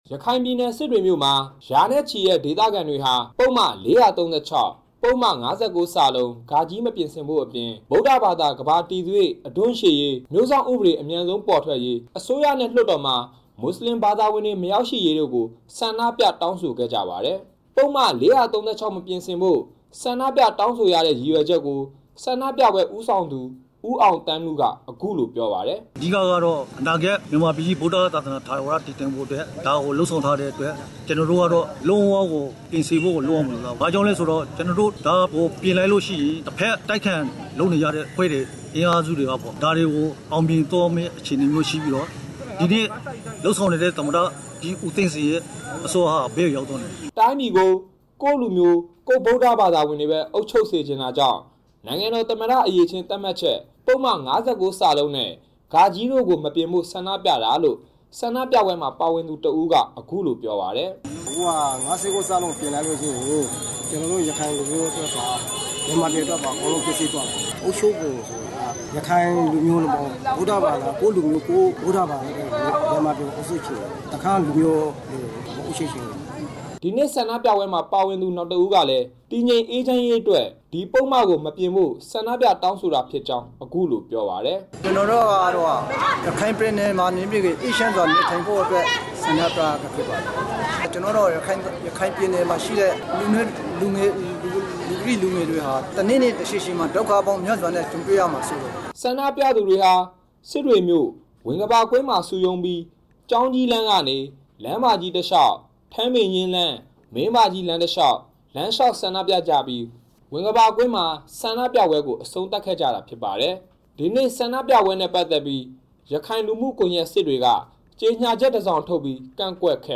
Ms. Kyung-Wha Kang နဲ့ တွေ့ဆုံခဲ့တဲ့ စစ်တွေမြို့က အမည်မဖေါ်လိုတဲ့ မွတ်စလင် ရပ်မိရပ်ဖတစ်ဦးက အခုလိုပြောပါတယ်။